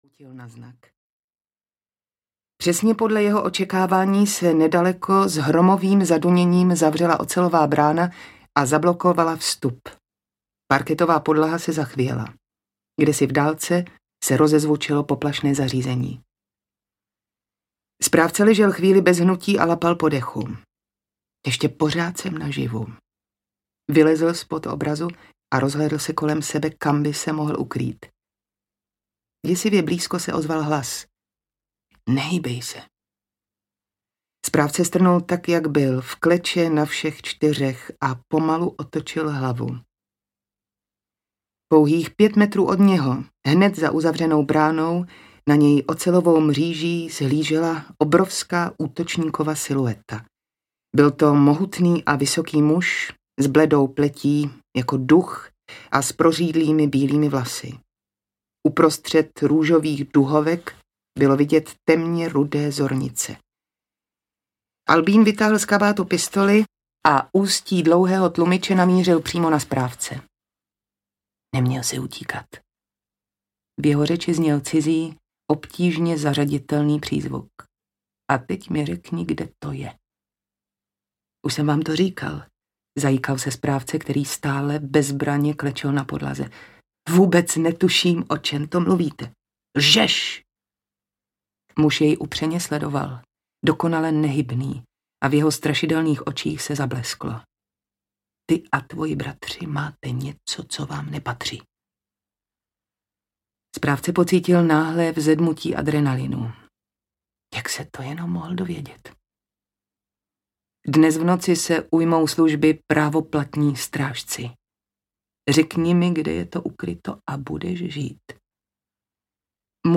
Šifra mistra Leonarda audiokniha
Ukázka z knihy